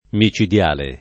mi©idL#le] agg.